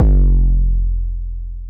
808 (FashionBass).wav